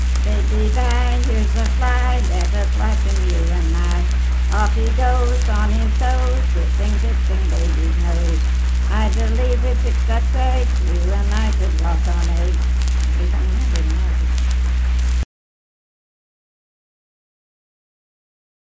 Unaccompanied vocal music performance
Children's Songs, Dance, Game, and Party Songs
Voice (sung)